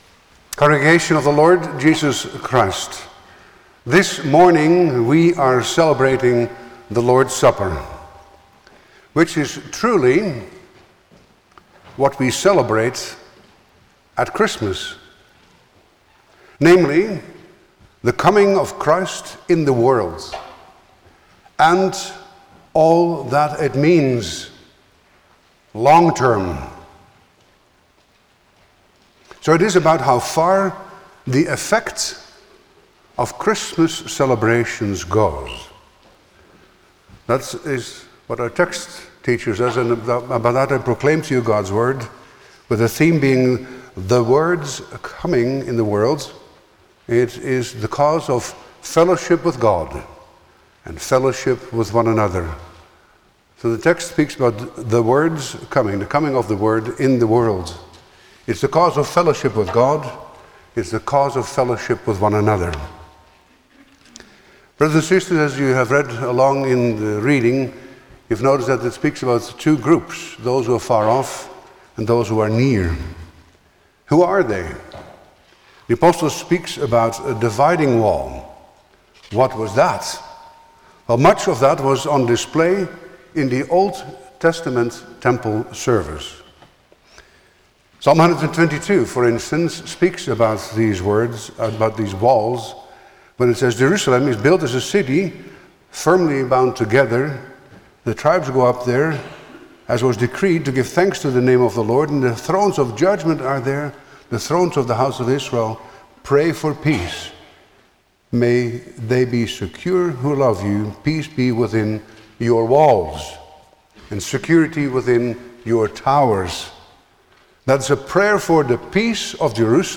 Service Type: Sunday morning
10-Sermon.mp3